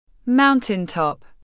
Mountaintop /ˌmaʊn.tɪnˈtɒp/